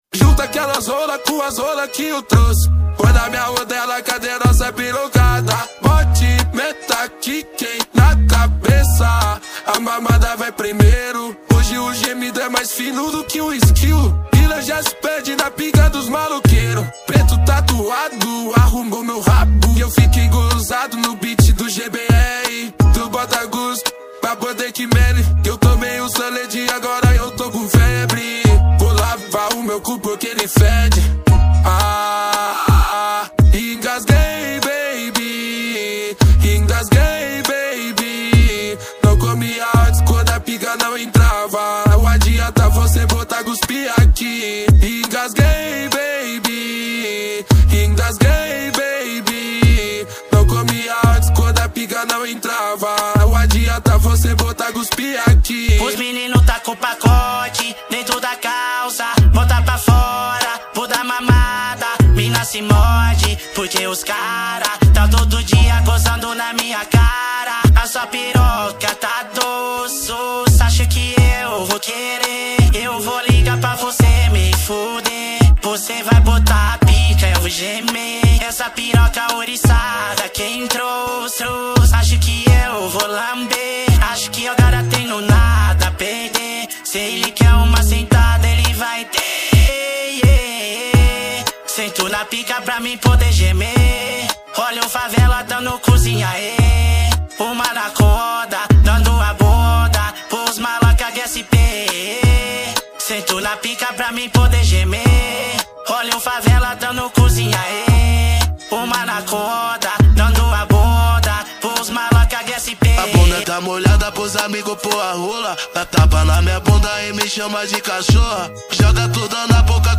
2024-04-21 15:01:07 Gênero: Trap Views